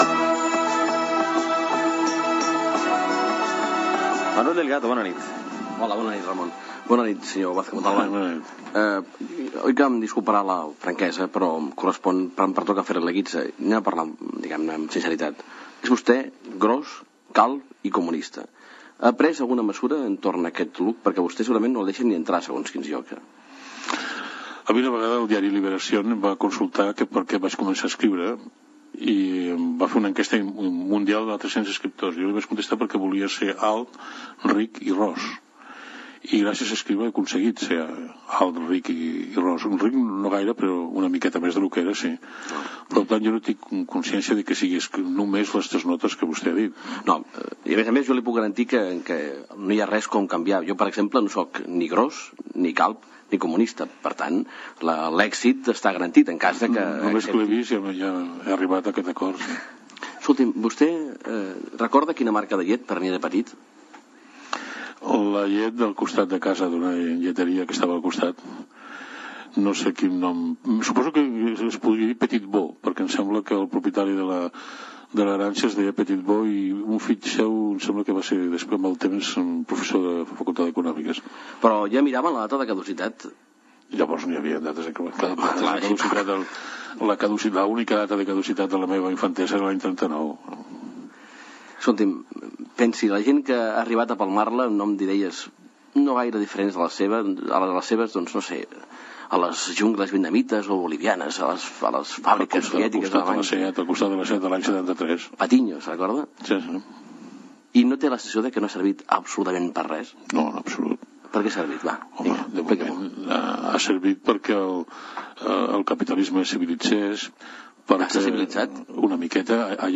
L'antropòleg Manuel Delgado entrevista l'escriptor Manuel Vázquez Montalbán a la secció "La guitza"